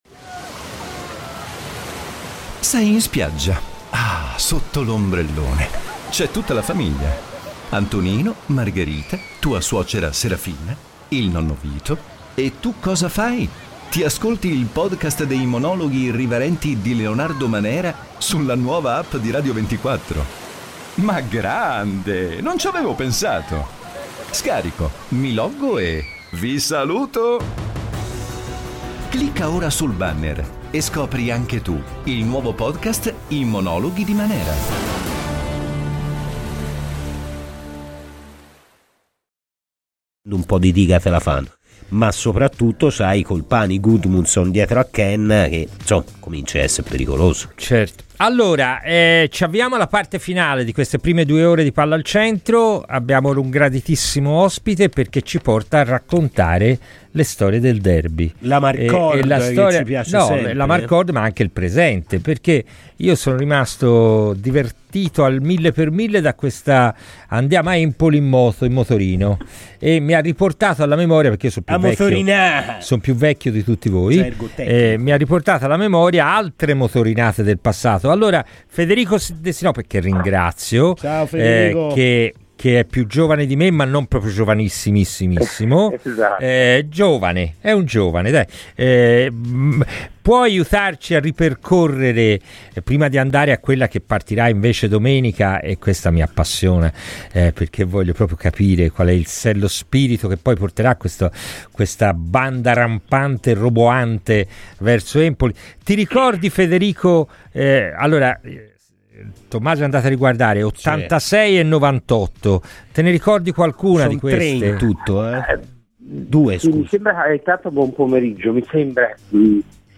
è intervenuto ai microfoni di Radio FirenzeViola nella trasmissione "Palla al Centro".